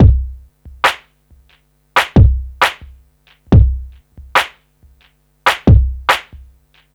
C E.BEAT 3-R.wav